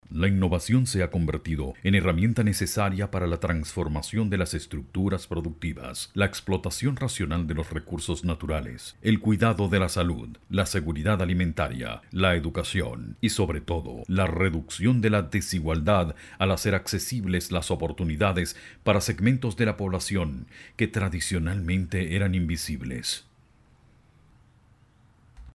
Locutor comercial, E learning y corporativo versátil
Sprechprobe: Industrie (Muttersprache):